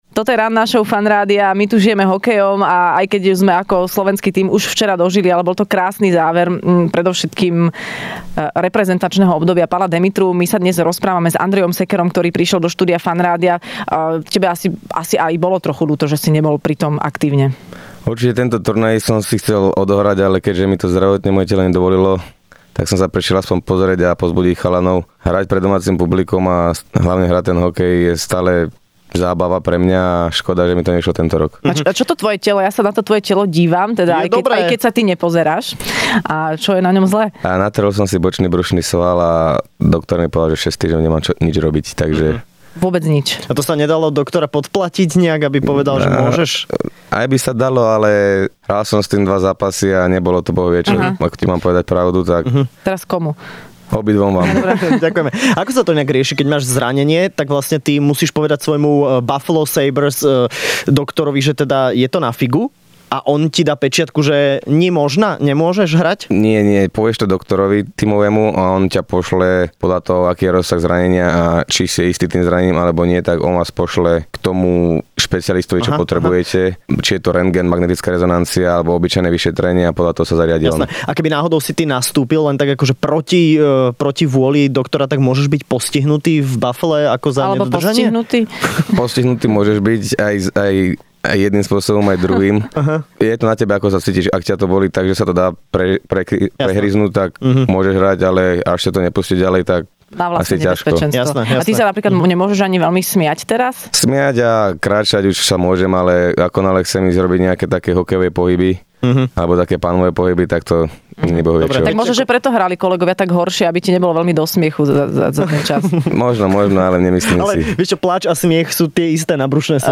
Hosťom v Rannej šou bol hokejový obranca, jeden z najlepších v NHL, Andrej Sekera